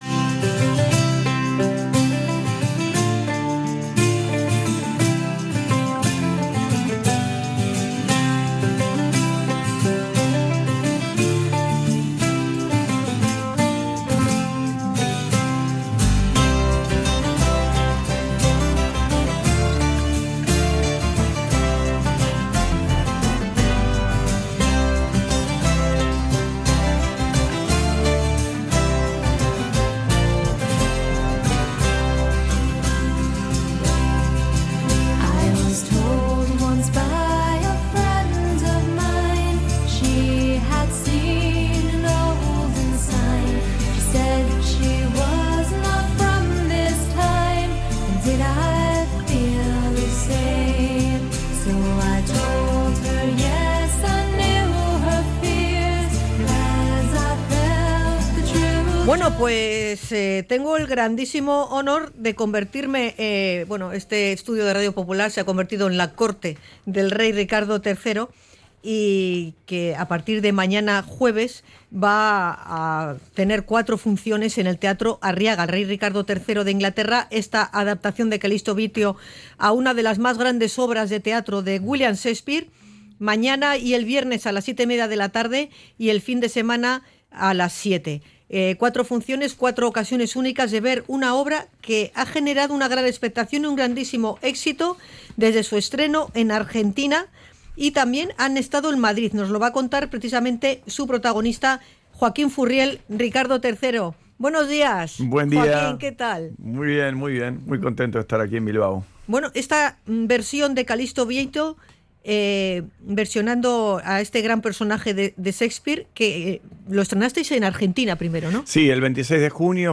El actor protagonista, Joaquín Furriel, ha visitado los estudios de Radio Popular